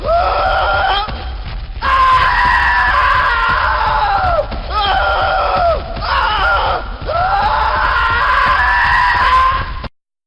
Play, download and share SCP-096 Chasing original sound button!!!!
scp-096-chasing.mp3